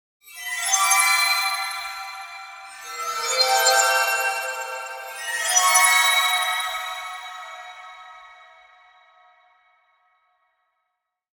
Eerie Mystical Stinger Sound Effect
An eerie mystical stinger with a suspenseful, haunting tone. Perfect for horror, fantasy, and cinematic projects.
Eerie-mystical-stinger-sound-effect.mp3